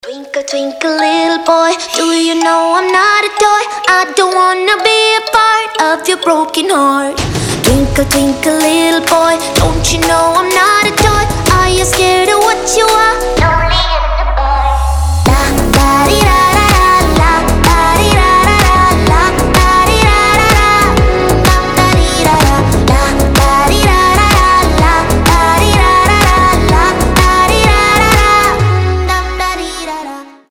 • Качество: 320, Stereo
забавные
женский голос
нарастающие
озорные
bounce
Музыкальная шкатулка